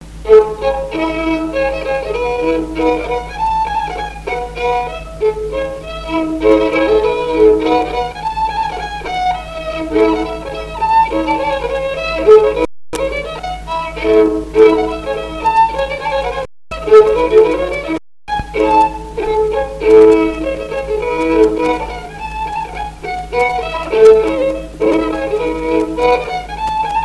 Scottish
danse : scottich trois pas
Pièce musicale inédite